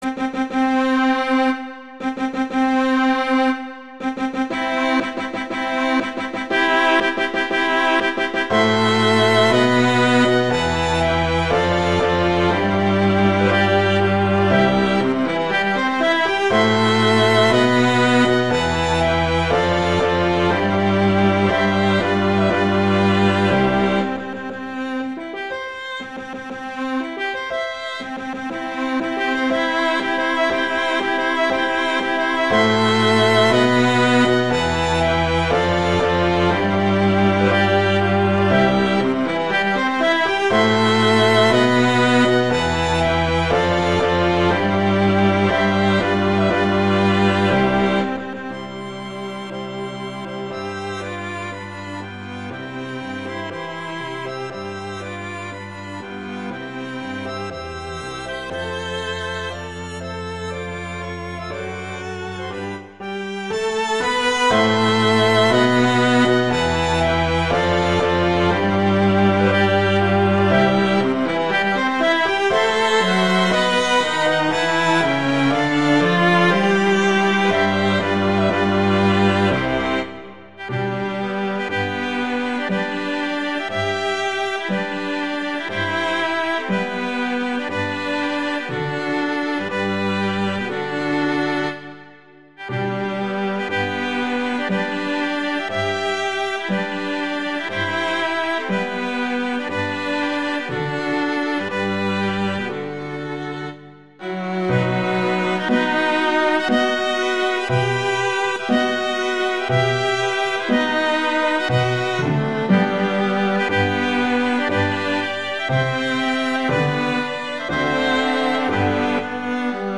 Instrumentation: piano quintet
arrangements for piano quintet
wedding, traditional, classical, festival, love, french